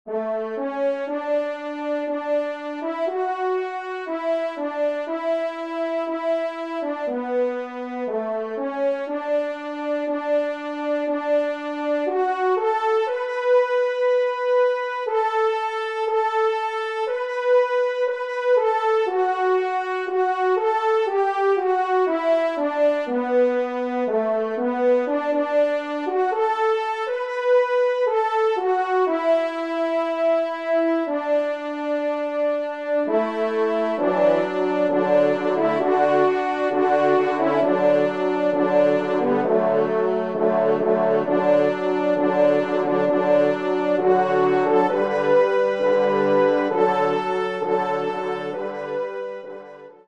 Auteur : Chant Traditionnel Écossais
ENSEMBLE